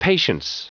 Prononciation du mot patience en anglais (fichier audio)
Prononciation du mot : patience